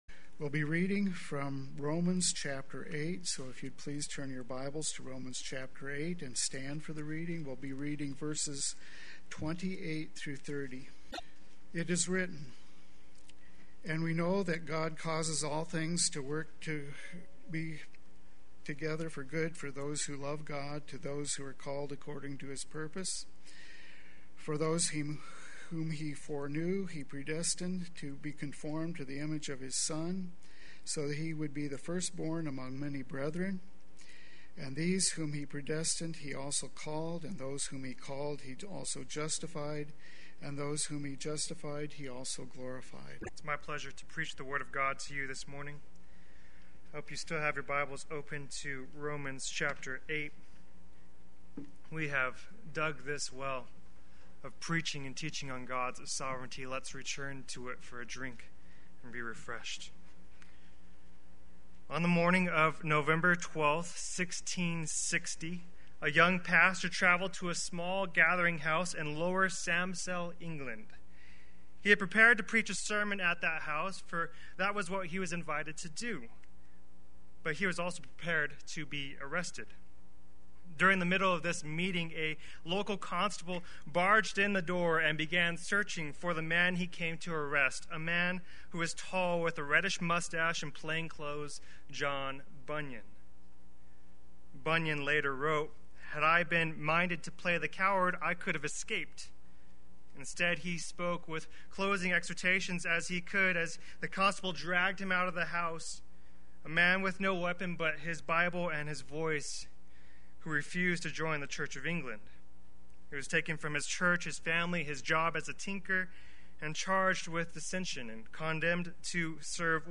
Play Sermon Get HCF Teaching Automatically.
God is Sovereign Sunday Worship